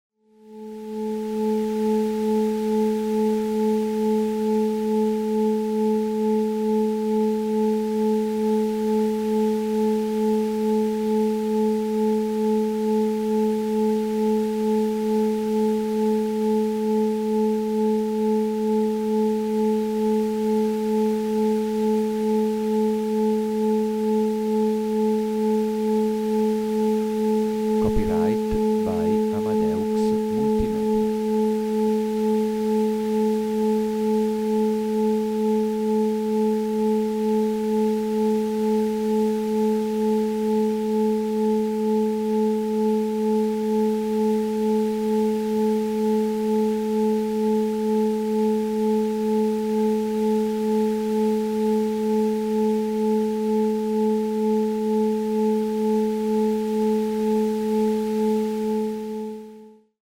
38.AT034-delta-isochronic-demo
AT034-delta-isochronic-demo.mp3